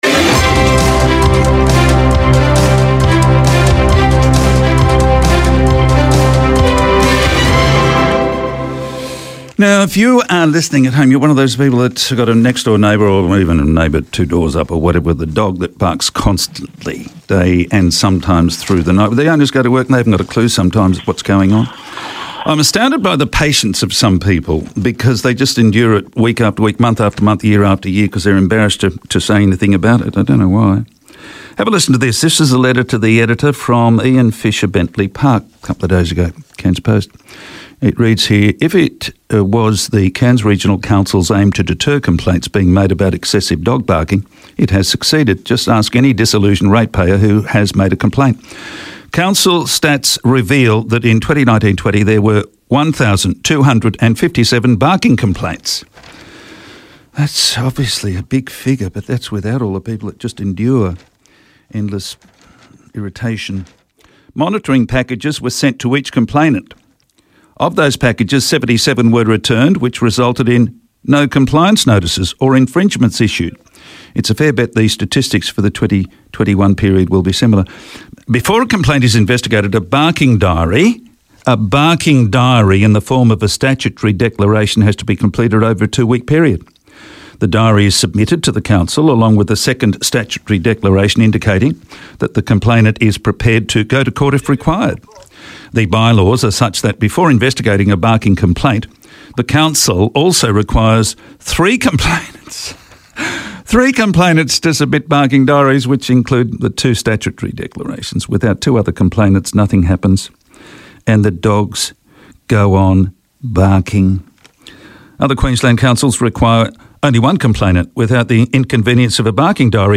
Today I spoke to Councillor Cathay Zeiger, the representative for Division 3, about dogs barking and the importance of microchipping your pets.